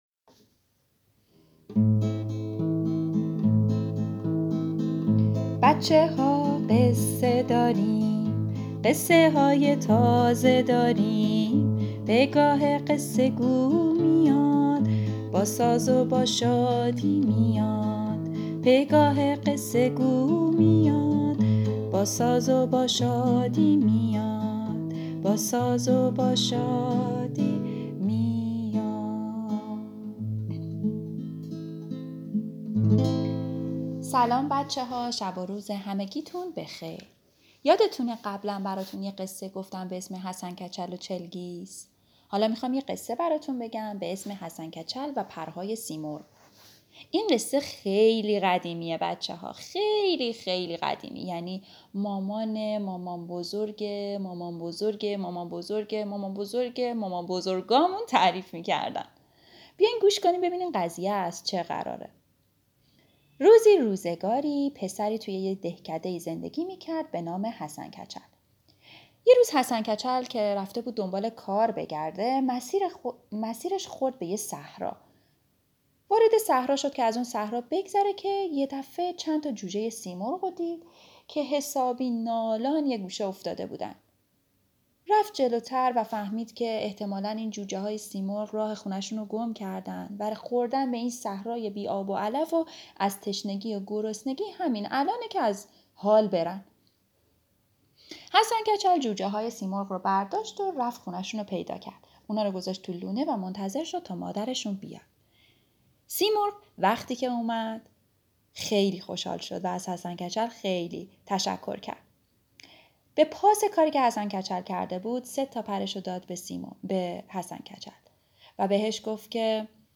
قصه صوتی کودکان دیدگاه شما 3,323 بازدید